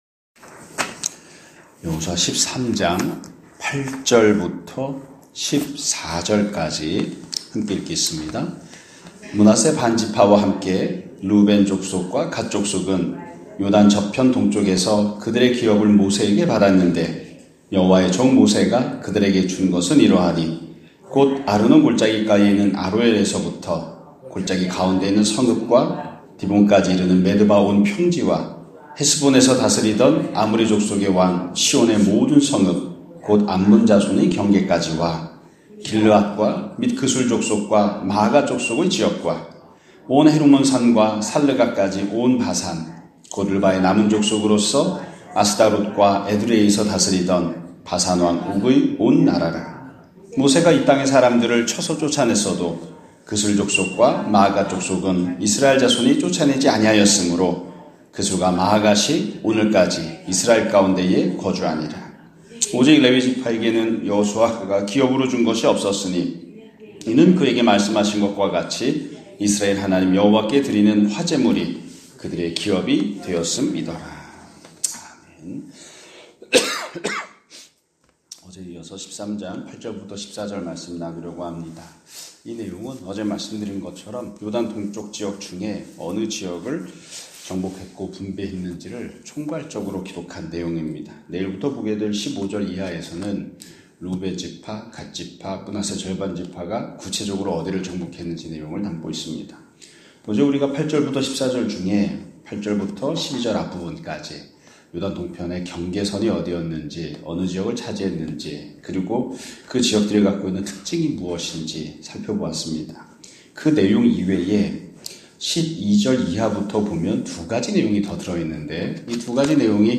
2024년 11월 19일(화요일) <아침예배> 설교입니다.